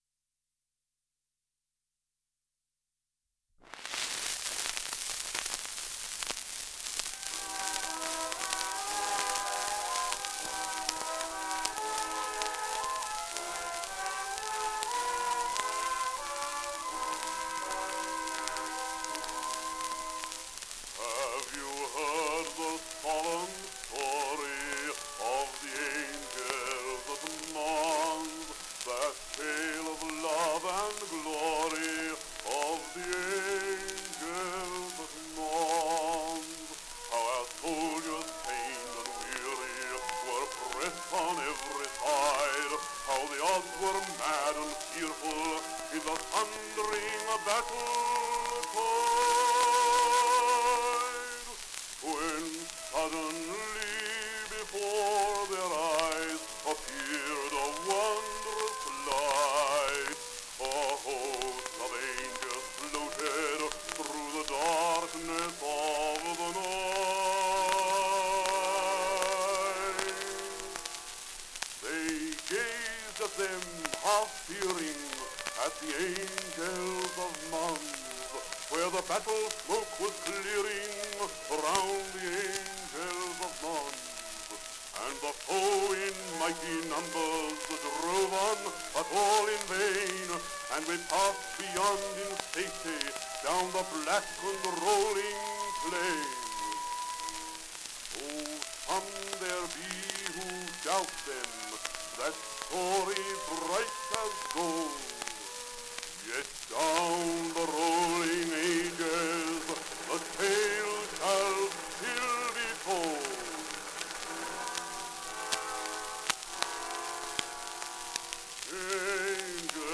78 RPM recording
baritone